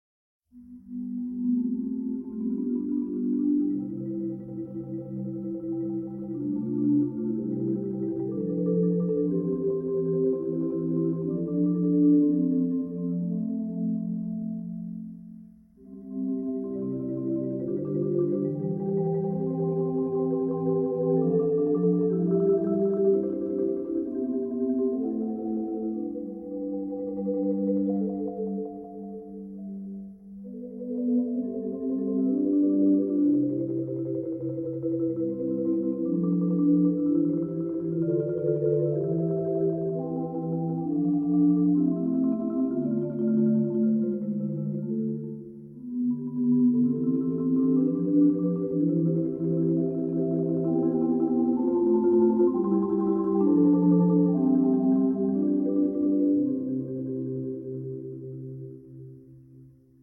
chamber percussion group